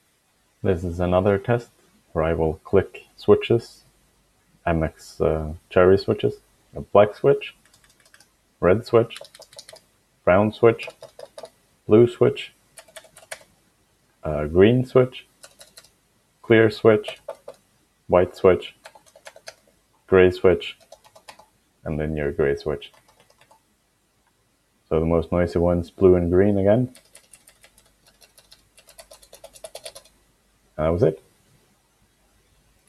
lapeltest_switches.mp3